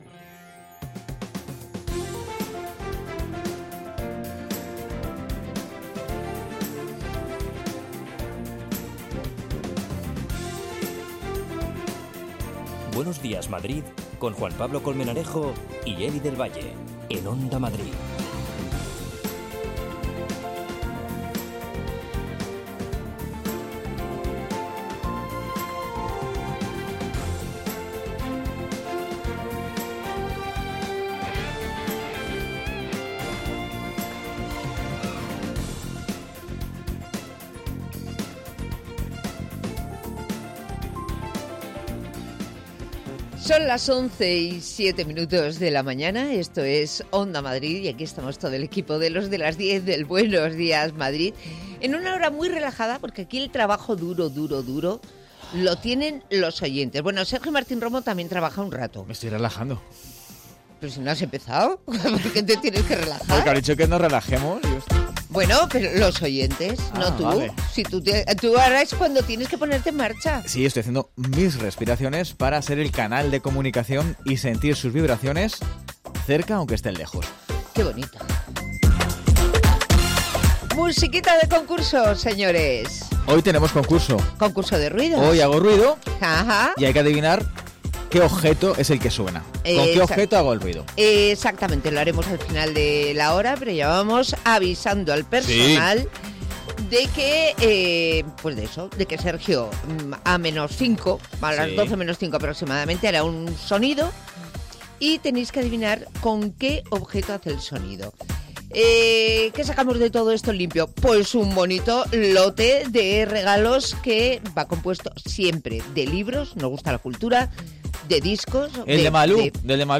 Tres horas más de radio donde se habla de psicología, ciencia, cultura, gastronomía, medio ambiente, consumo….